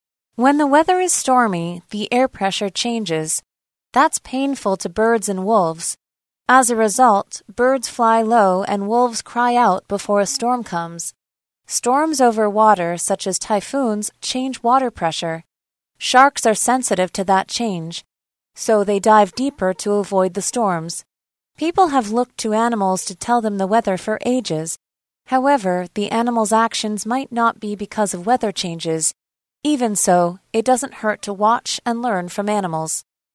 朗讀題目及練習音檔請參閱附加檔案~~